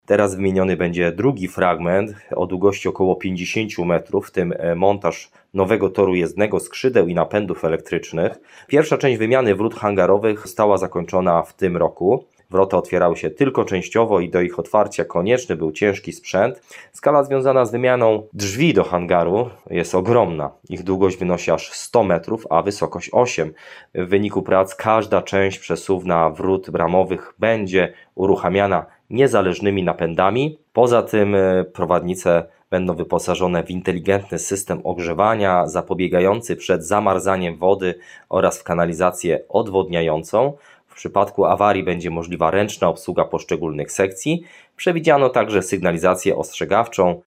– dla Twojego radia mówił